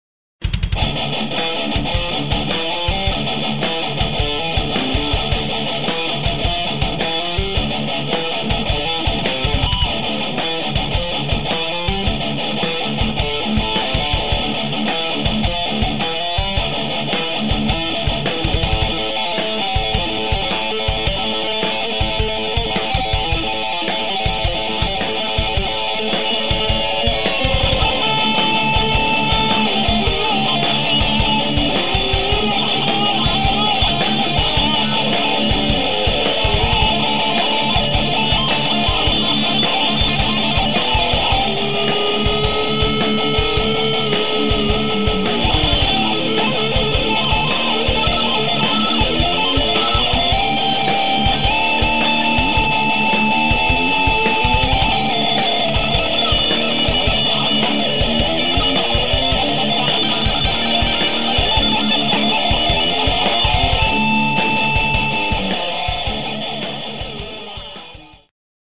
Melodic Metal